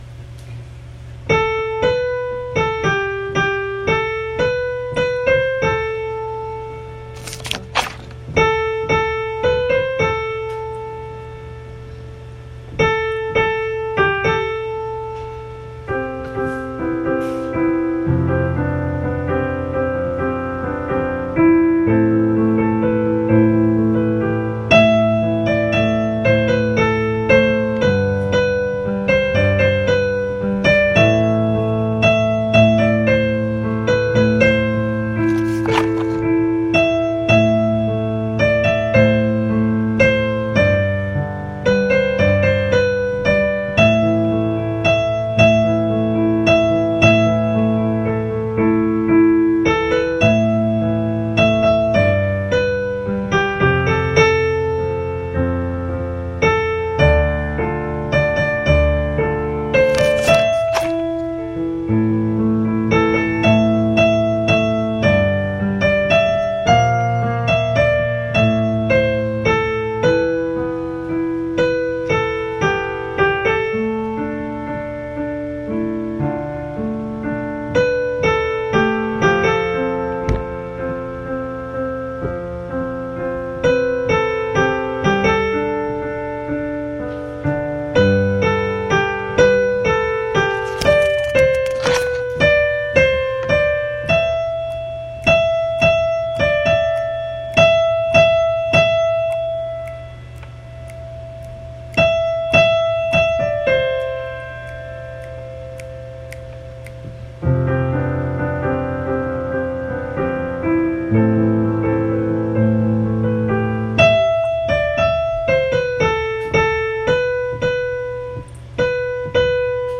Soprano 1Download Soprano 1 Rehearsal Track
2-White-Birds-Soprano-1.mp3